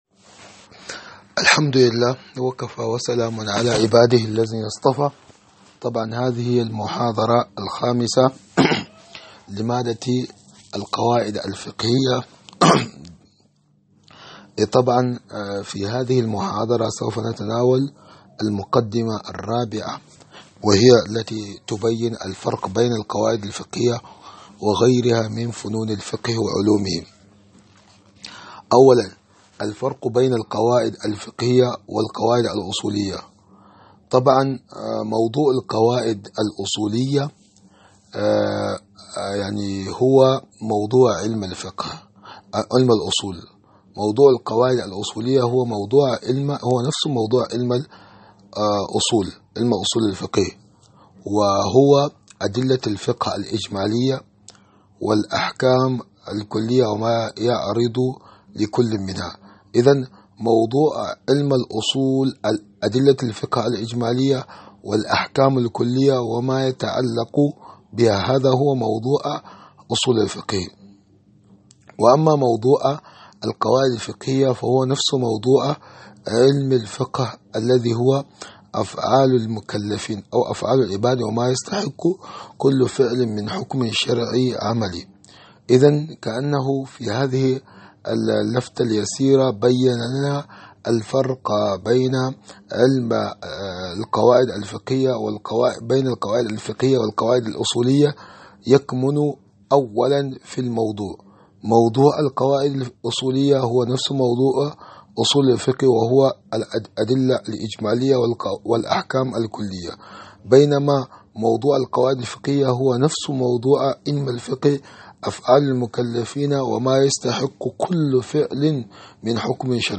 محاضرة مادة القواعد الفقهية 005